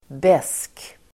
Ladda ner uttalet
Uttal: [bes:k]